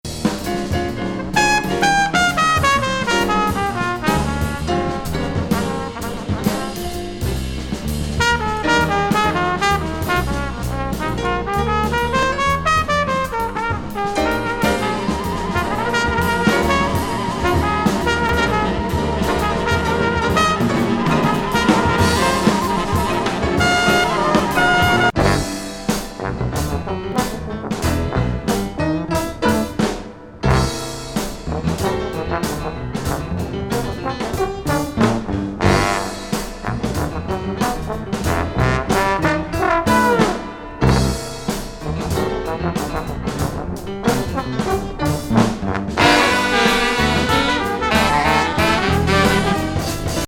ロフト・ジャズを代表するマルチリード奏者のオクテット編成’87年作。
フリージャズを通過した音色が刺激的なスウィングチューン
ニューオリンズ風なフリーフォーム・ジャズファンク